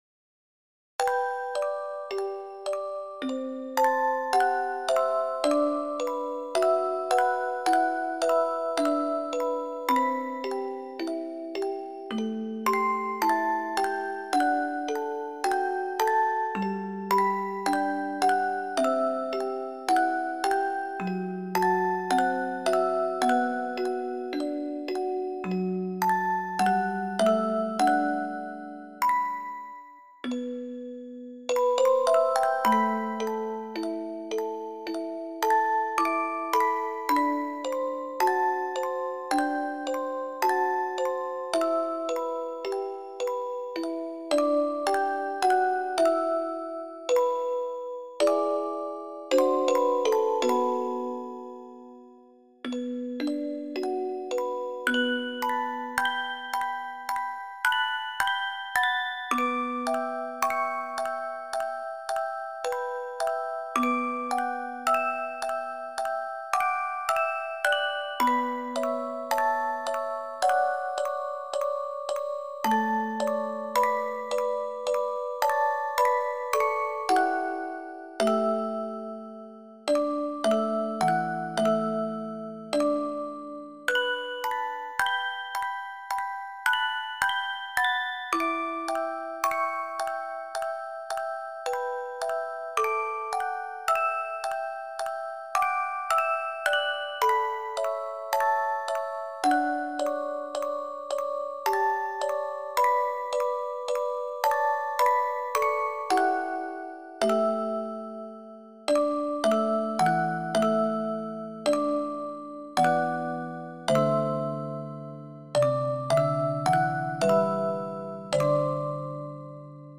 TV・CM曲【2】－MP3オルゴール音楽素材
オルゴール チェレスタ ミュージックボックス